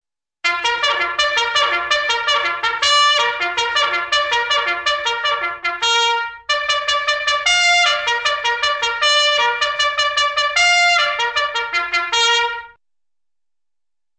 army bugle reveille Meme Sound Effect
army bugle reveille.mp3